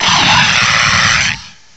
cry_not_toucannon.aif